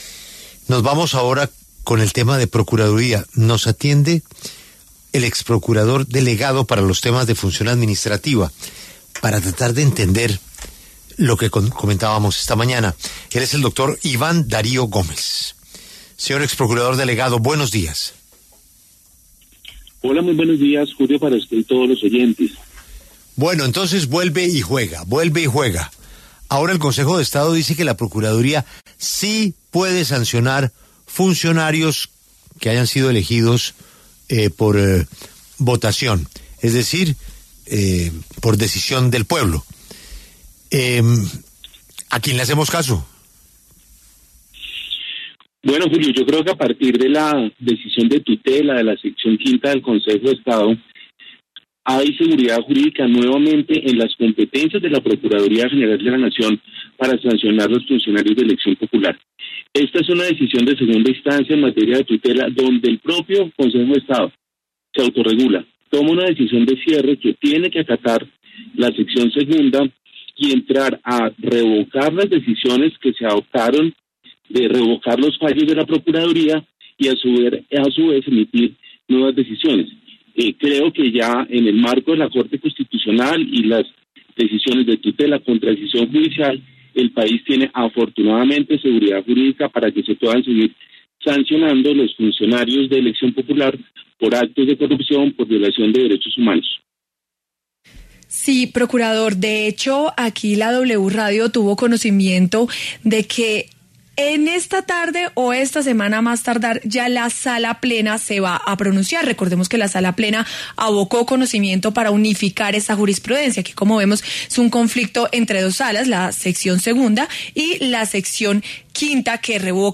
La W habló con el exprocurador delegado para la Función Administrativa, Iván Darío Gómez Lee, sobre la reciente decisión de tutela de la Sección Quinta del Consejo de Estado acerca de la competencia de la Procuraduría para sancionar a funcionarios elegidos por voto popular.